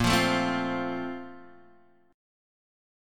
A#M7sus2 chord